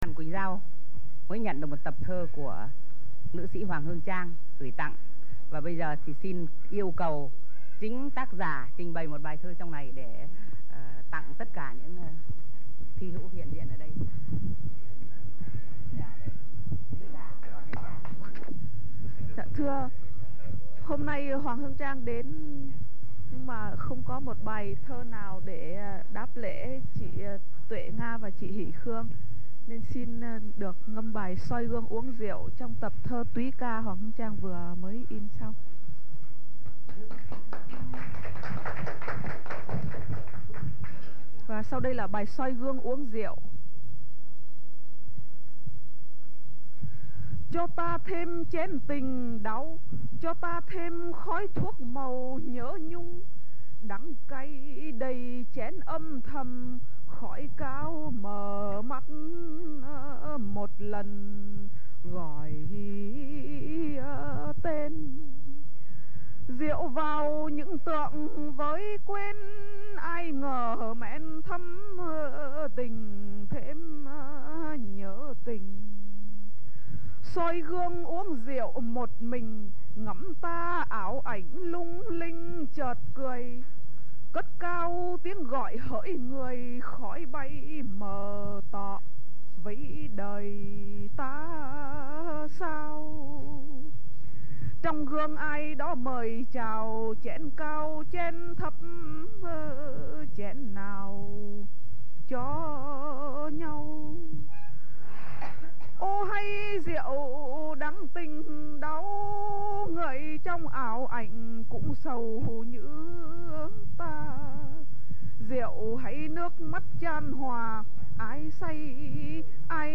Bài này tuy ngâm hơi nhanh, có lẽ v́ cảm khái, nhưng do chính tác giả ngâm, không c̣n ai có tư cách ngâm hay hơn nữa..